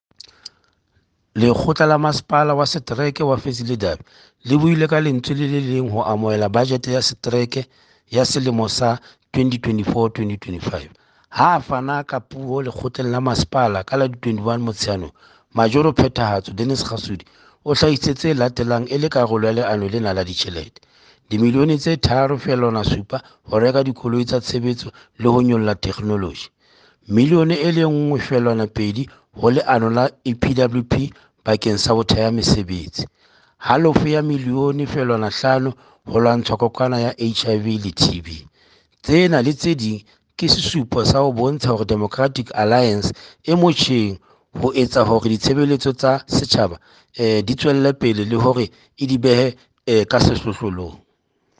Sesotho by Cllr Stone Makhema.
Sotho-voice-Stone.mp3